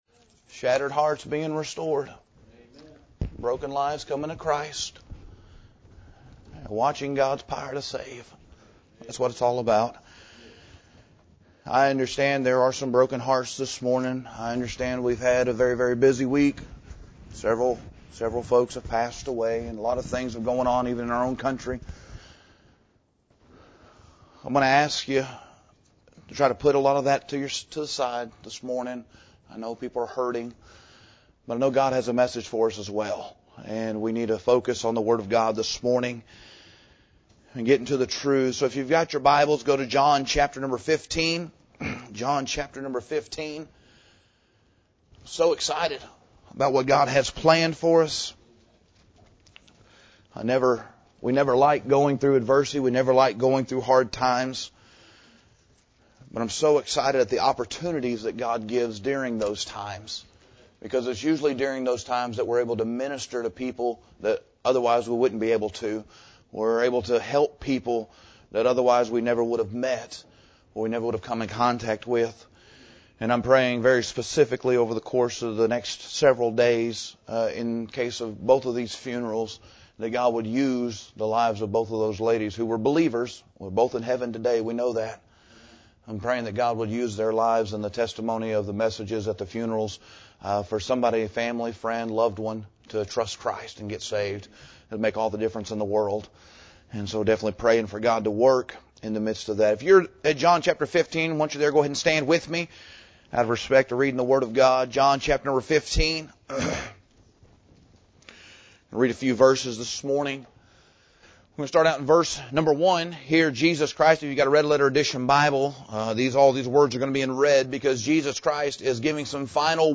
This sermon draws from John chapter 15 and the surrounding context of our Lord’s final instructions to His disciples on the way to Gethsemane.